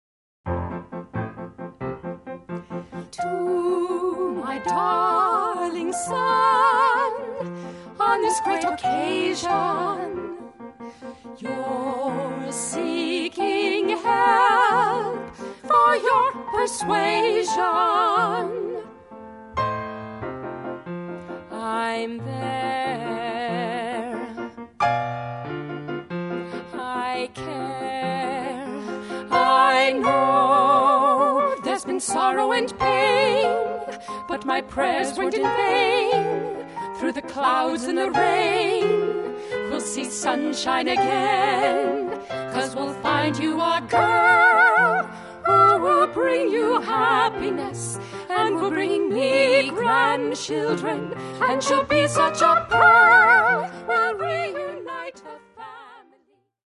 --comedy music parody